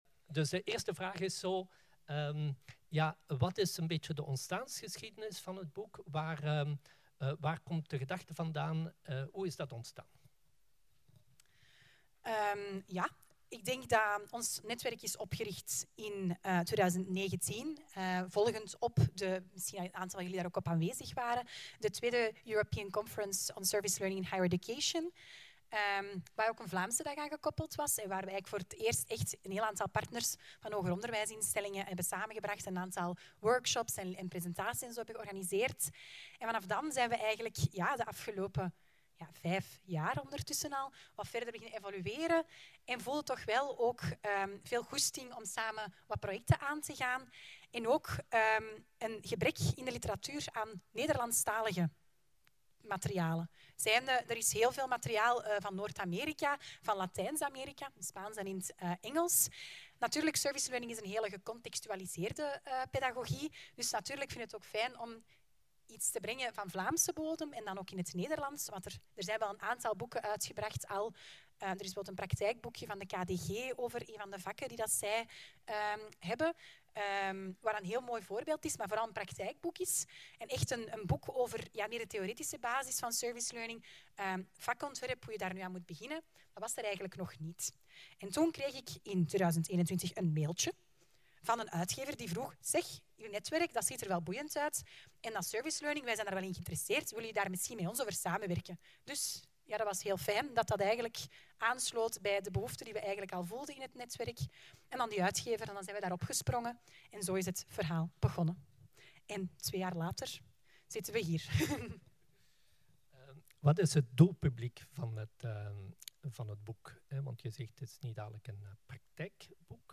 Boek-SL-interview-AudioExtracted.mp3